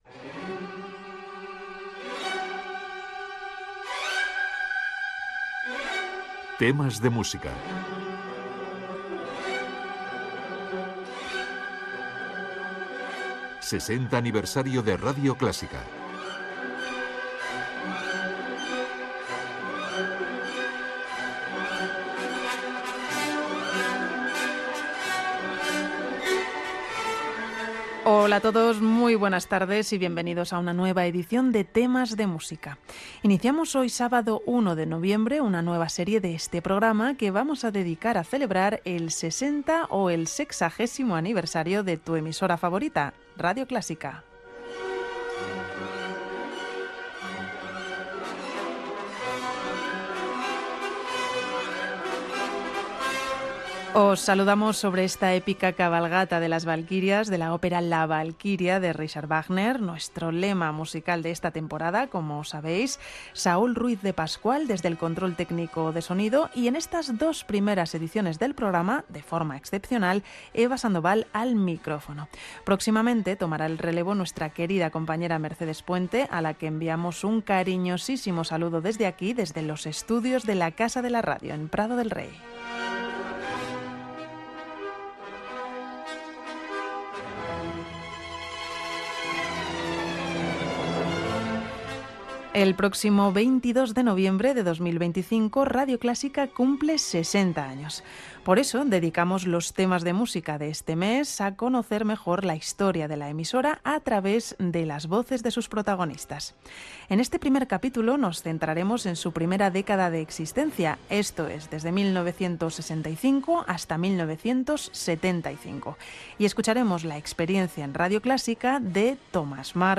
Paraules de Tomás Marco , interpretació de Mompou als estudis de RNE, a l'any 1973, records professionals de Tomás Marco.
Gènere radiofònic Musical